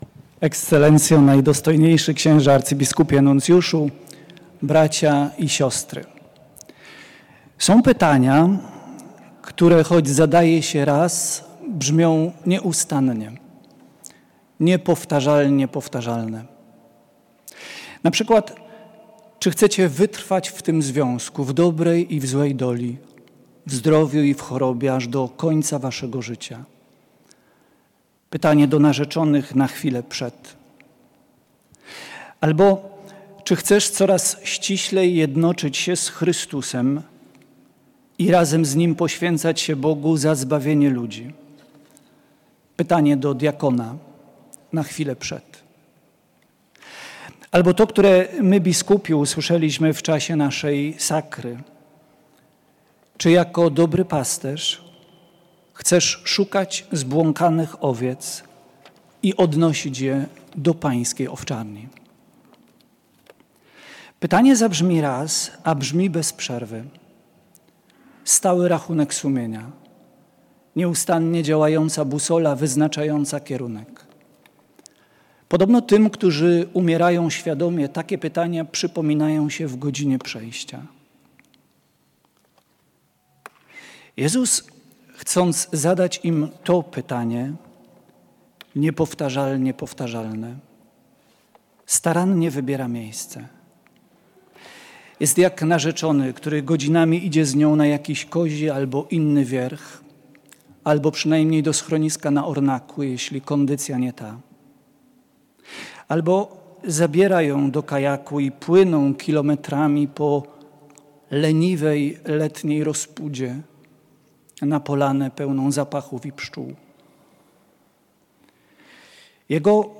W poniedziałek, 14 marca, w Świątyni Opatrzności Bożej została odprawiona uroczysta Msza Święta z okazji Narodowego Święta Watykanu, obchodzonego w rocznicę wyboru Ojca Świętego na Stolicę Piotrową.
Wzruszające, pełne głebokiej treści kazanie, nawiązujące do powołania św. Piotra Apostoła na papieża, wygłosił arcybiskup Adrian Galbas SAC, koadiutor z diecezji katowickiej.
kazanie-abp.-Adriana-na-Mszy-sw.-za-papieza.mp3